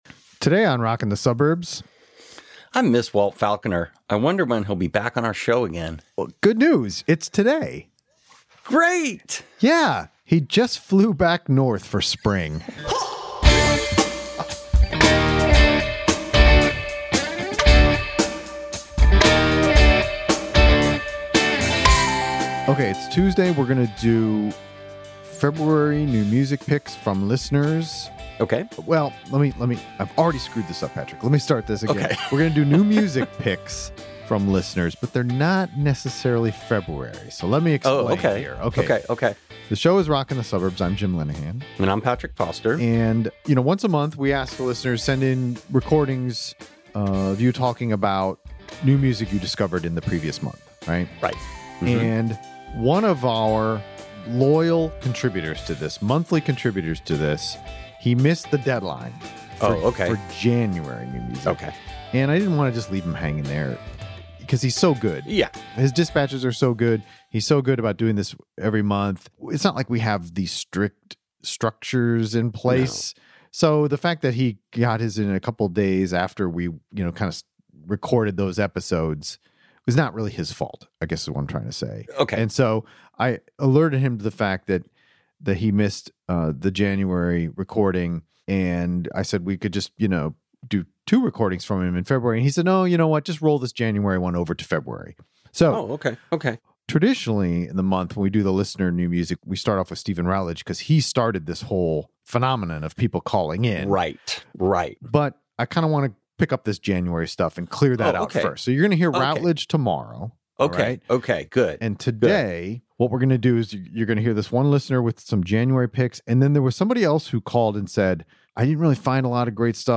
We take two listener calls about new music.